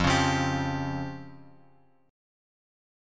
Fsus2#5 chord {1 4 3 x x 3} chord